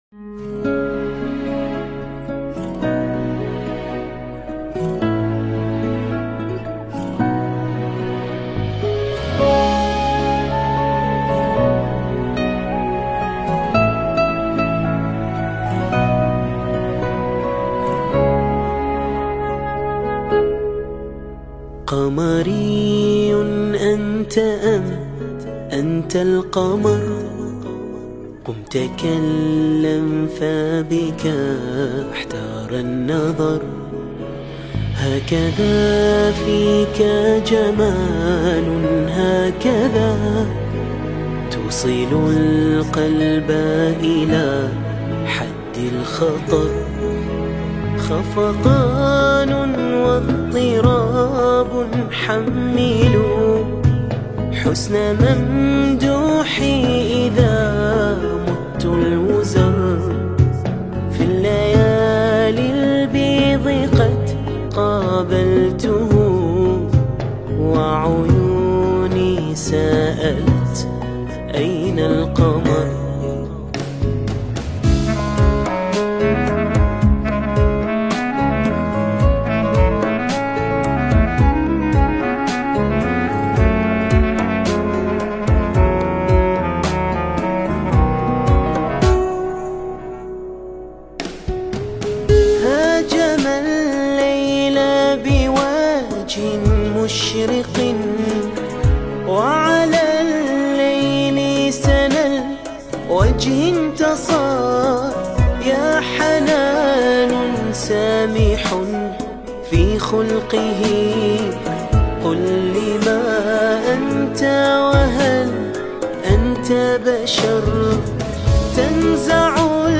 في ذكرى مولد أمير المؤمنين علي بن أبي طالب عليه السلام
أداء وألحان المنشد